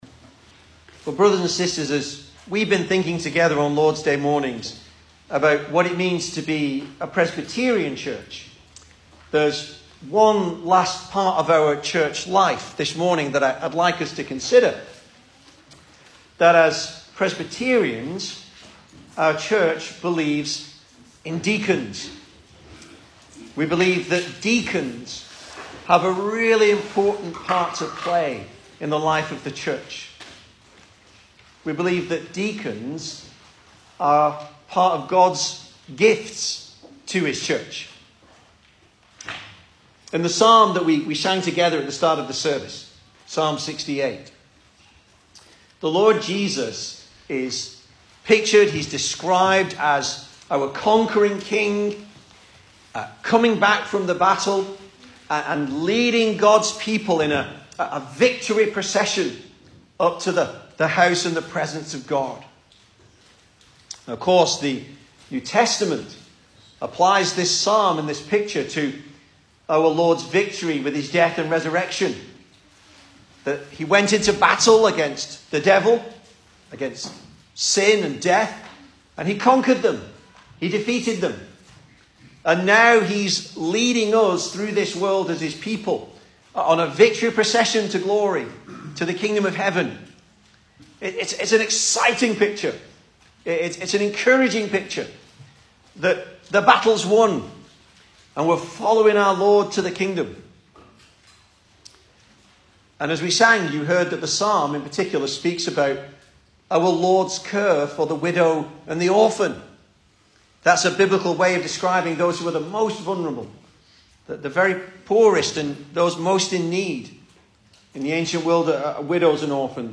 2022 Service Type: Sunday Morning Speaker